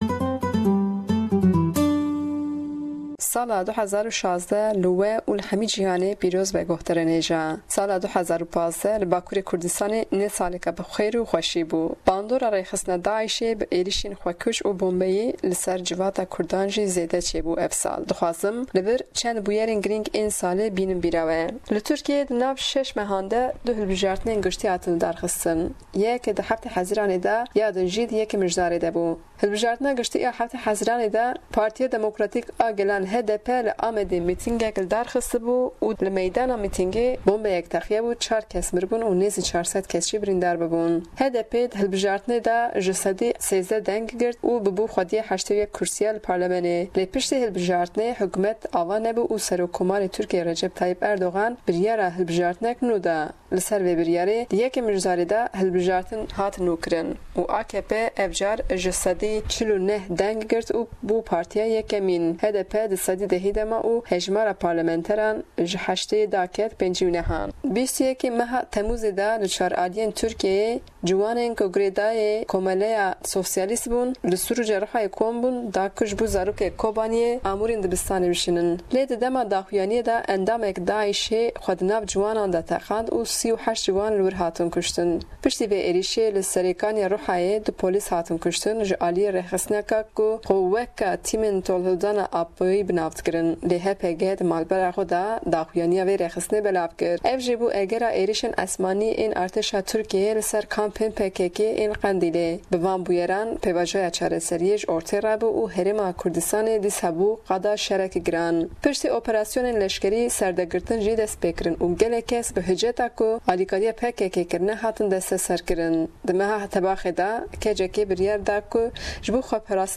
Raporta ji Diyarbekir û rûdanên 2015 li Tirkiyê